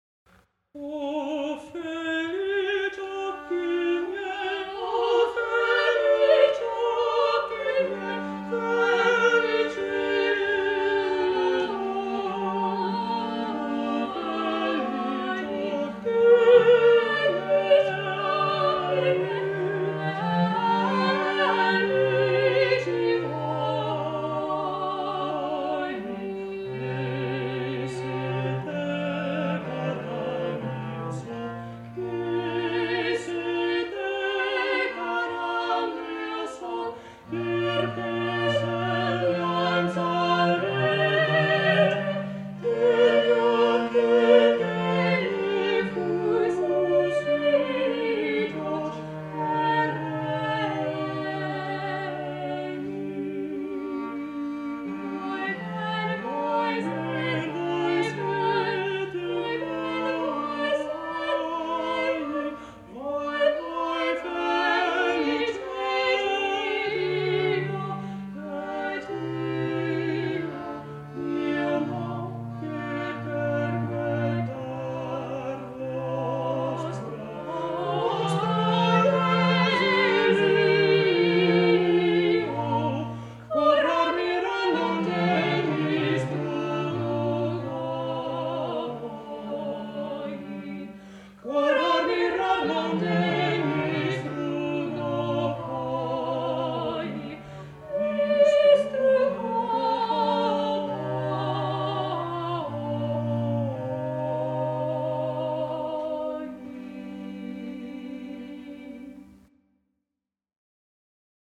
This madrigal a4 is found in the 1539 print
soprano
countertenor